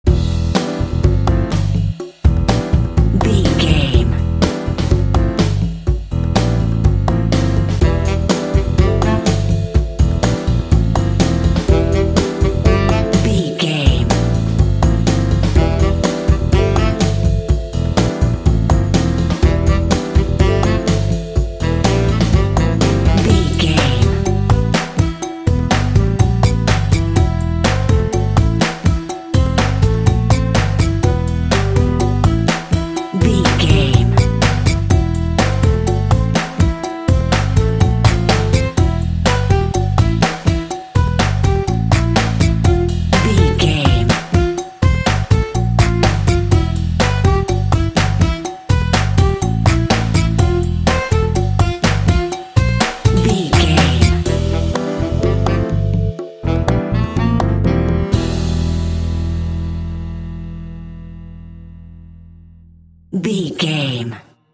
Phrygian
funky
happy
bouncy
groovy
piano
drums
saxophone
bass guitar
jazz
Funk